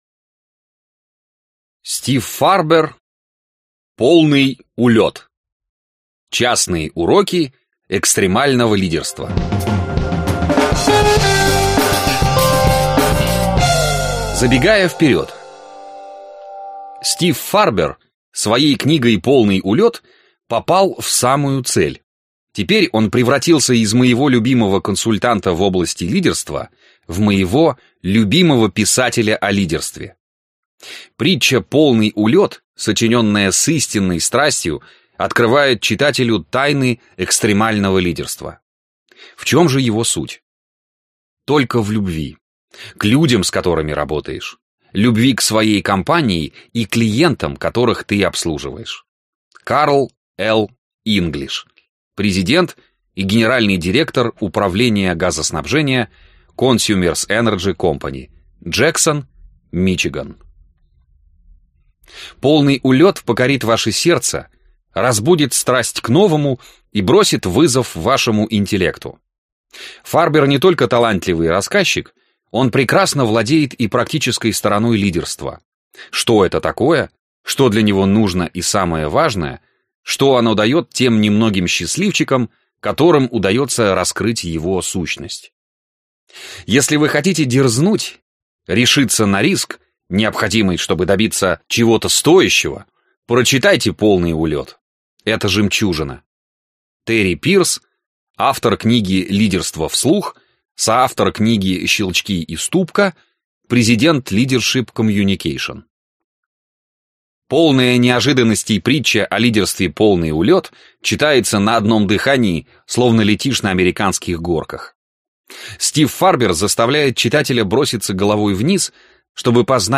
Аудиокнига Полный улет. Частные уроки экстремального лидерства | Библиотека аудиокниг